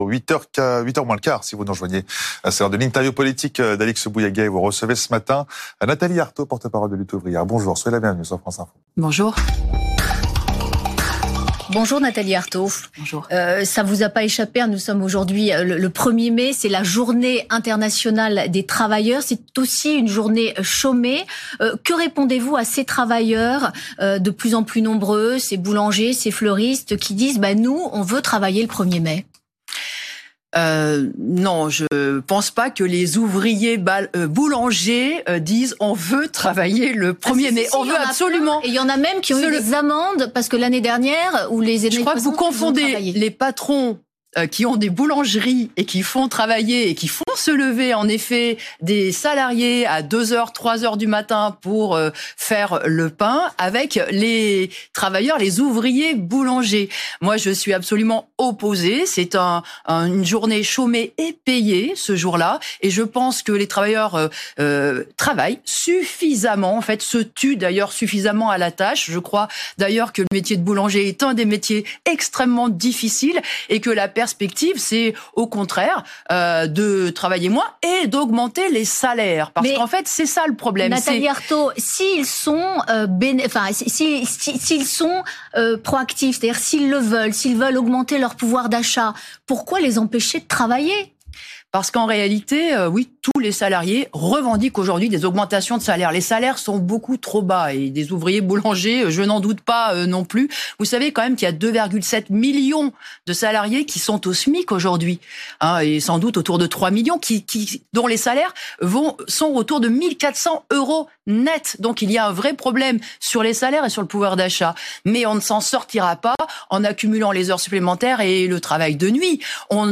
France Info TV : Interview du 1er mai matin